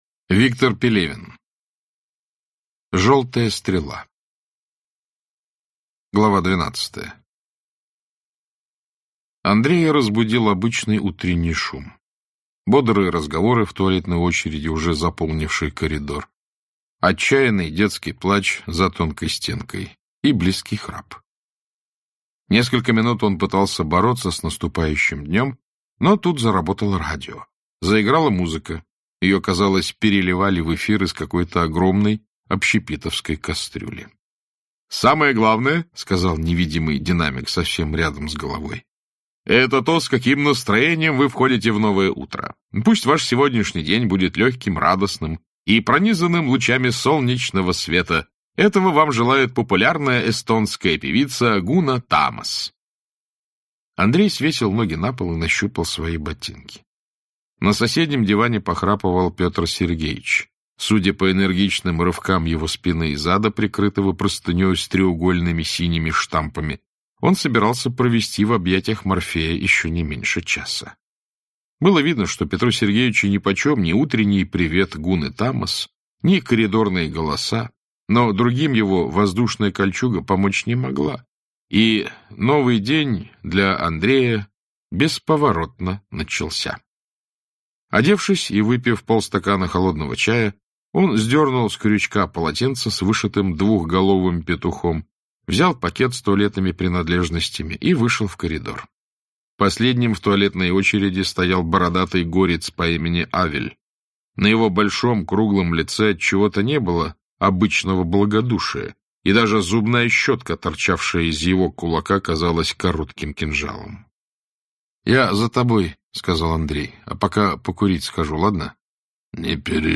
Aудиокнига Желтая стрела и другие повести Автор Виктор Пелевин Читает аудиокнигу Александр Клюквин.